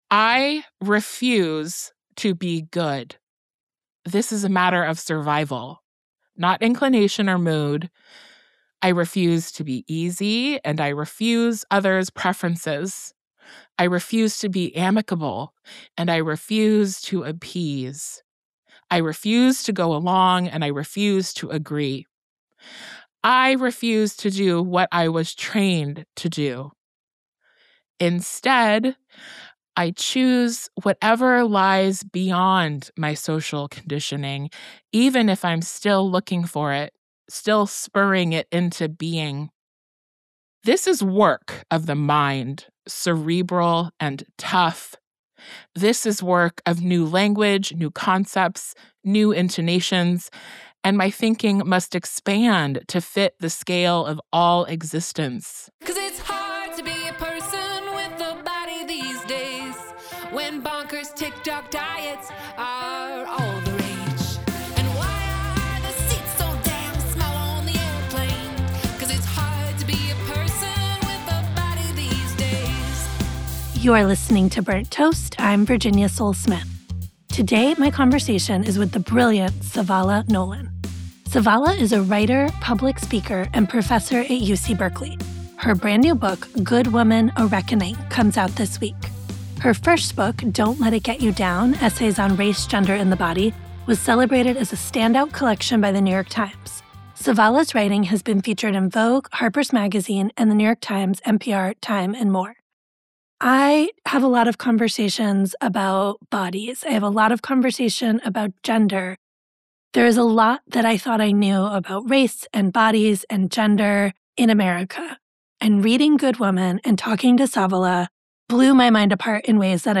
I have a lot of conversations about bodies.